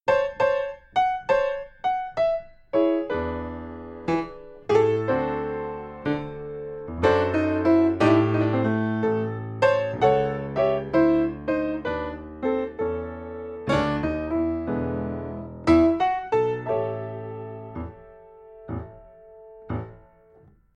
Genre jazz / bigband / blues
• instrumentation: piano